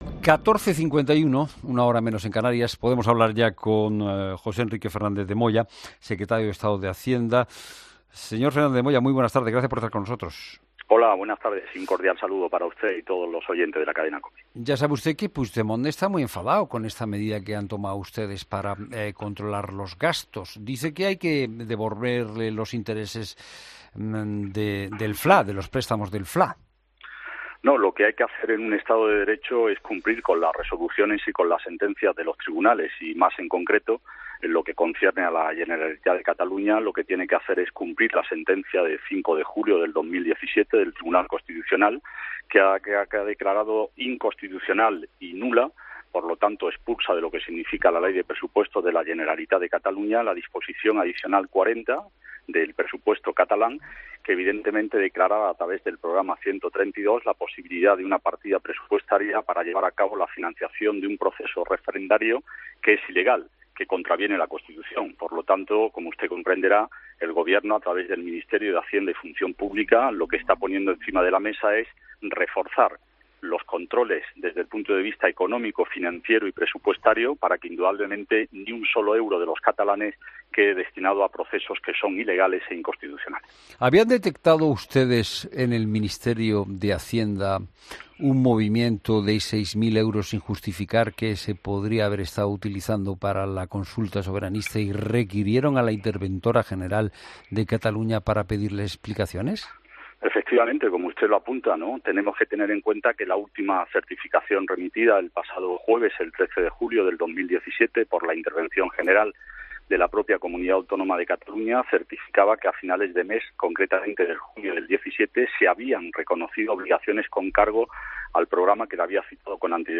ESCUCHA LA ENTREVISTA COMPLETA | José Enrique Fernández de Moya Romero, secretario de Estado de Hacienda